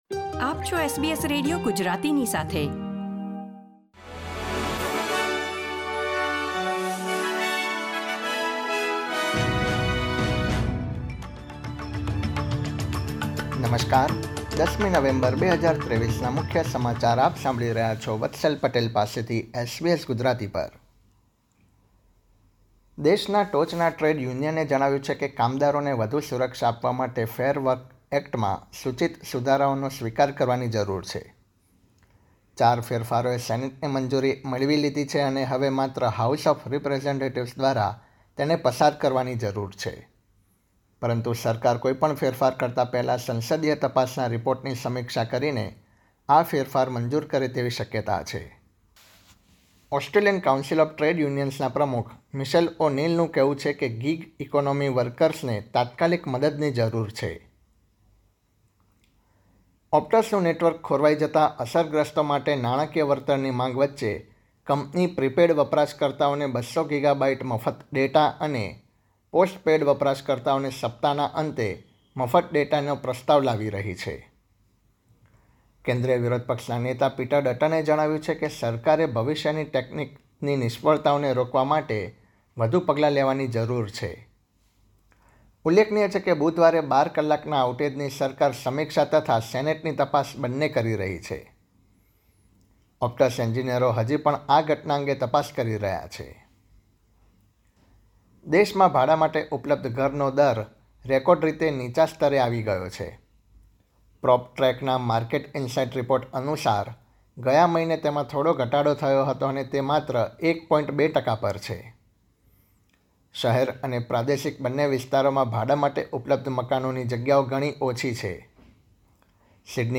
SBS Gujarati News Bulletin 10 November 2023